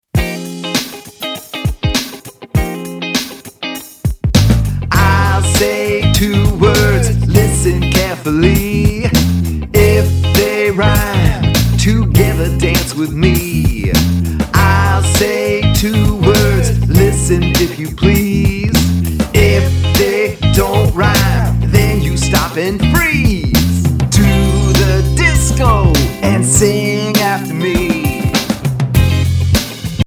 Listen to the open version of this song.